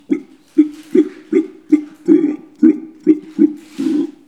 Les sons ont été découpés en morceaux exploitables. 2017-04-10 17:58:57 +02:00 736 KiB Raw History
bruit-animal_04.wav